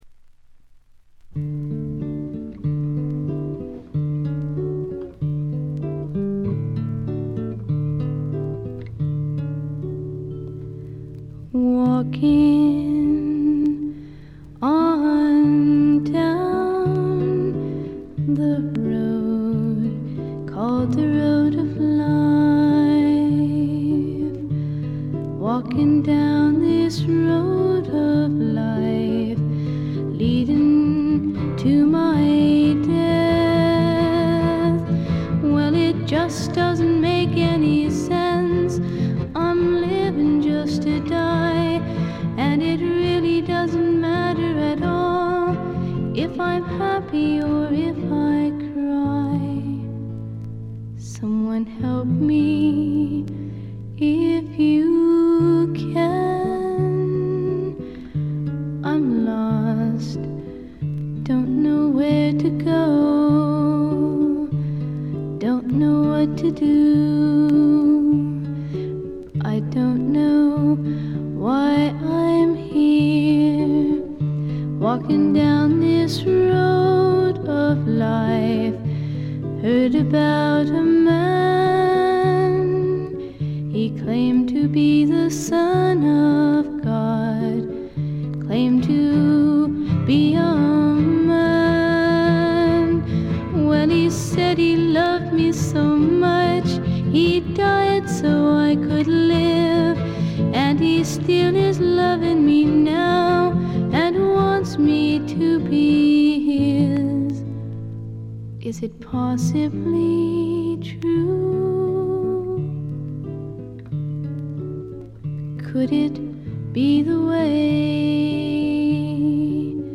静音部でバックグラウンドノイズ。
演奏はほとんどがギターの弾き語りです。
試聴曲は現品からの取り込み音源です。